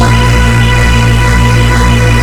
PADSTACK  -R.wav